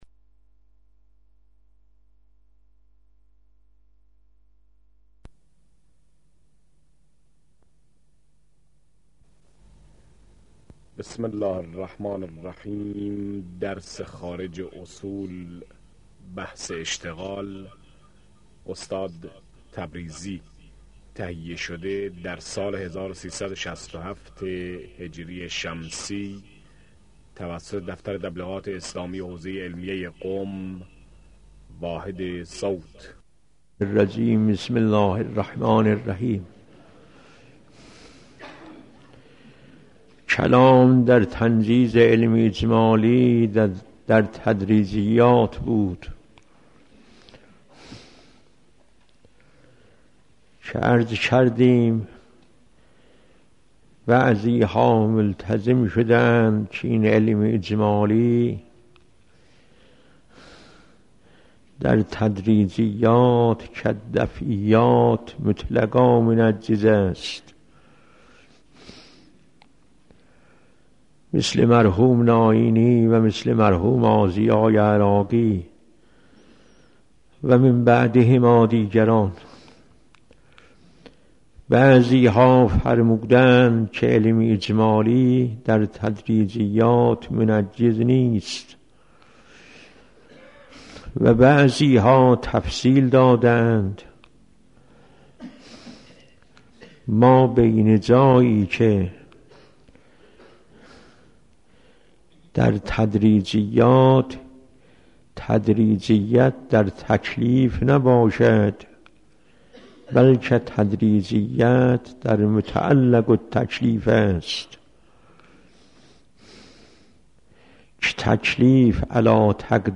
آیت الله جواد تبريزي - برائت | مرجع دانلود دروس صوتی حوزه علمیه دفتر تبلیغات اسلامی قم- بیان